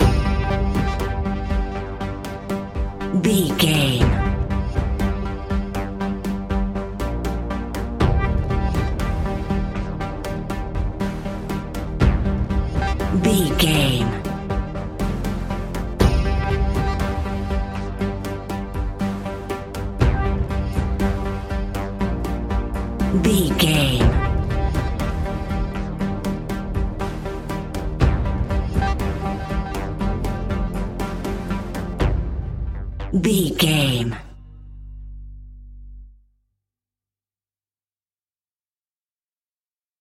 Aeolian/Minor
ominous
dark
haunting
eerie
synthesiser
drum machine
instrumentals
horror music